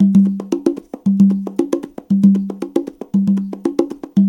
CONGA BEAT24.wav